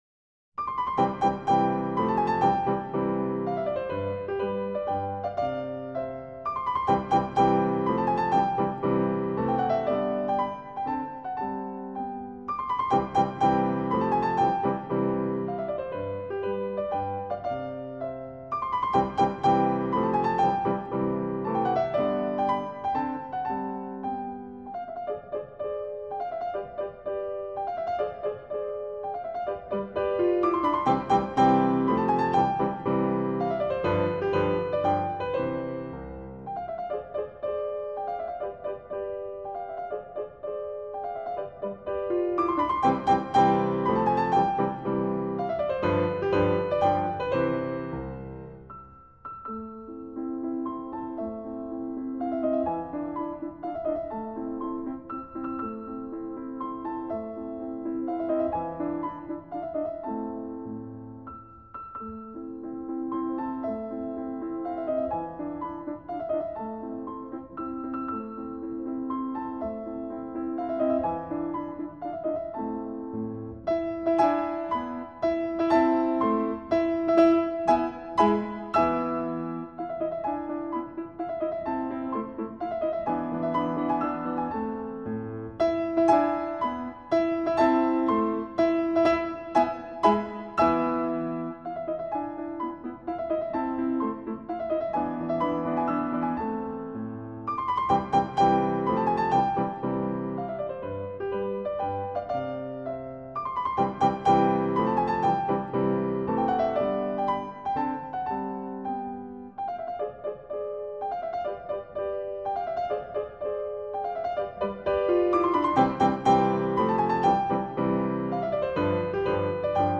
0266-钢琴名曲小步舞曲.mp3